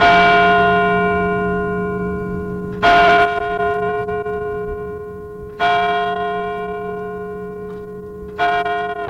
教堂铃音带故障
Tag: 现场记录 毛刺 胶带